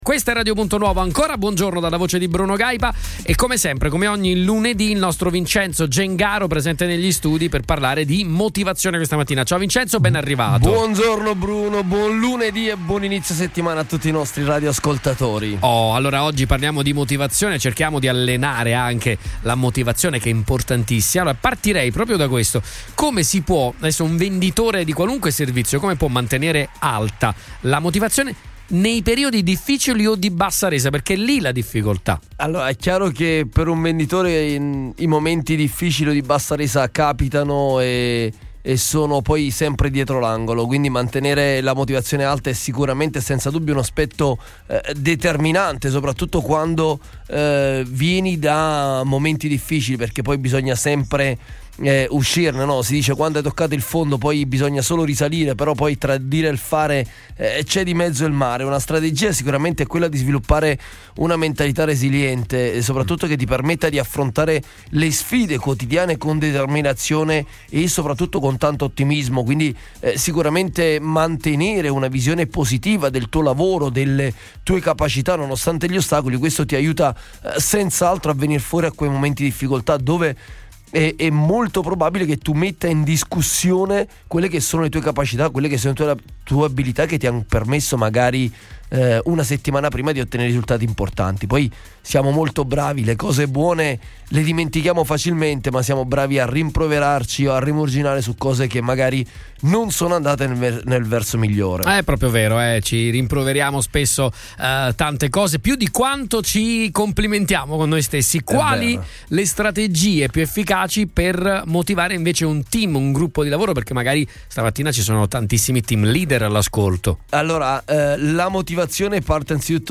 Mantenere alta la motivazione come venditore durante periodi difficili o di bassa resa può essere una sfida, ma ci sono diverse strategie che possono aiutare. Ne abbiamo parlato in diretta questa mattina
formatore e consulente alle vendite